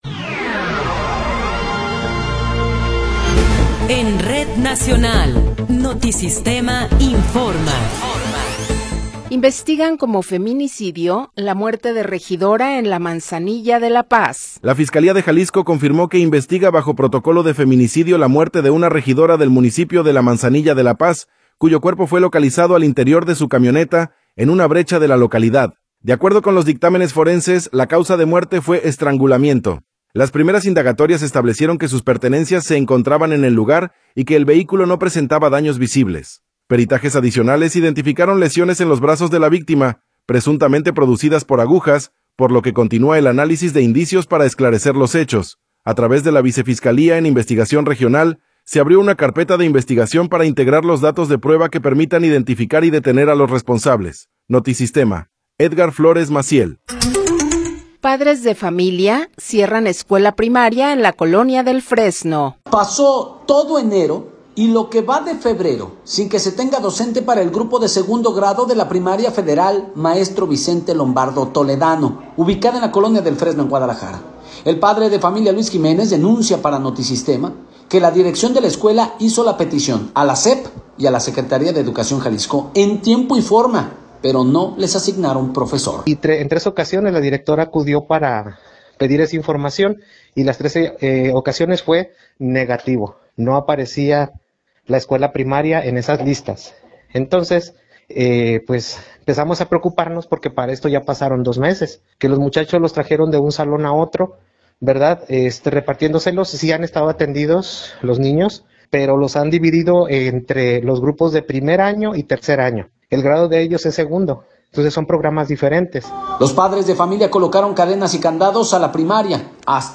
Noticiero 15 hrs. – 13 de Febrero de 2026